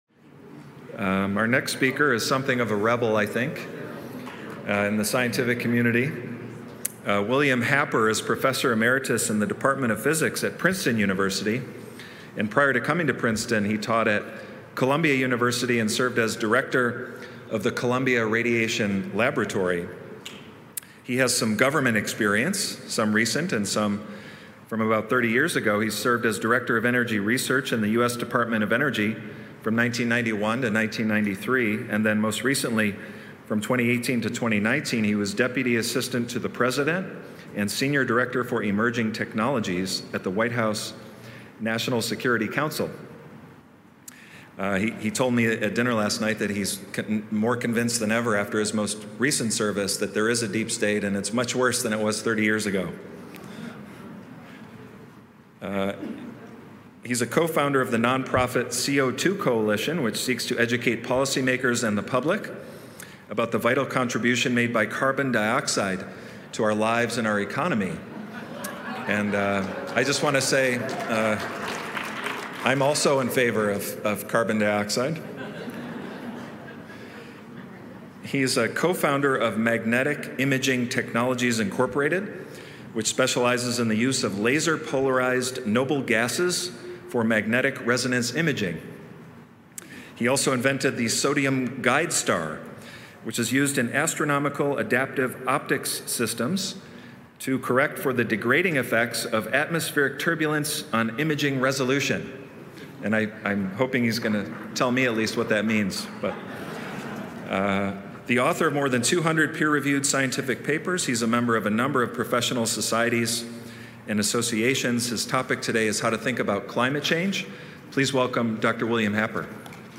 William Happer Professor of Physics Emeritus, Princeton University This speech was given at a Hillsdale College National Leadership Seminar on February 19, 2021, in Phoenix, Arizona.